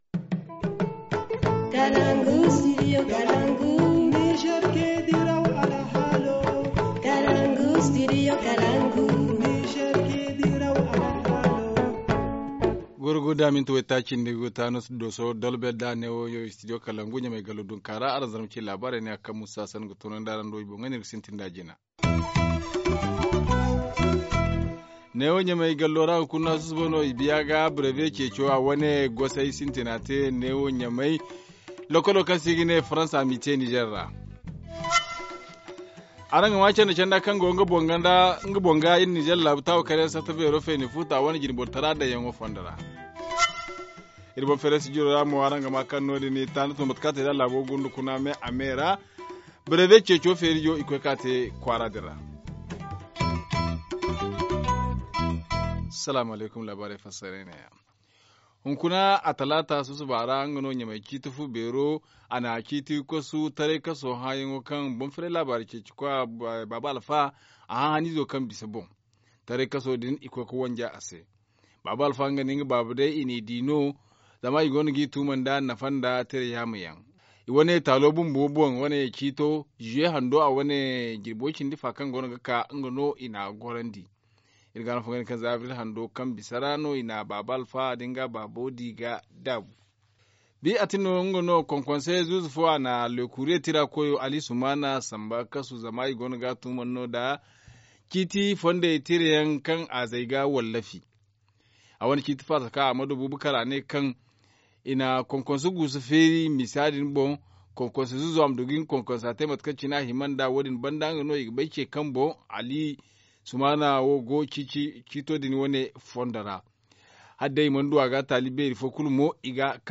1. Début ce matin des épreuves du Brevet d’Etudes du Premier Cycle dans toutes les régions du Niger. Extraits des interventions du directeur régional des enseignements secondaires et du président de la haute autorité de lutte contre la corruption et les infractions assimilés HALCIA, sur les mesures prises pour un meilleur déroulement des épreuves et sans fraude.